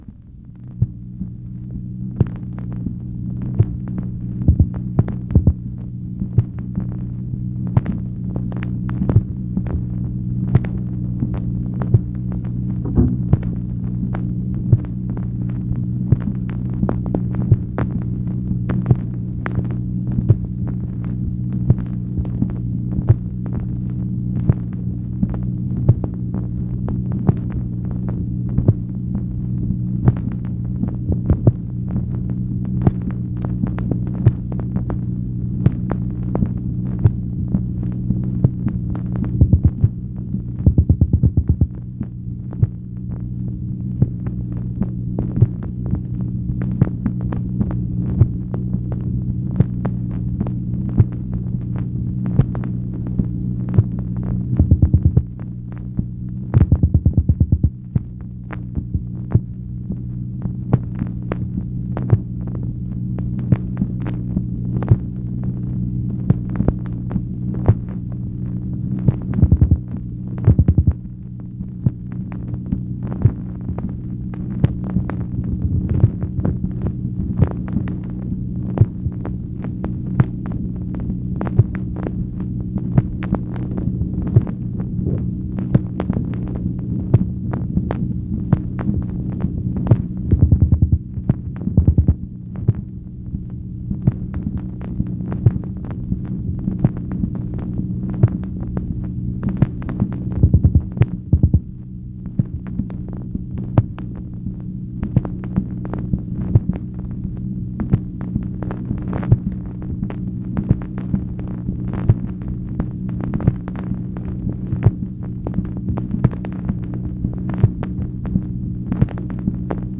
MACHINE NOISE, January 13, 1964
Secret White House Tapes | Lyndon B. Johnson Presidency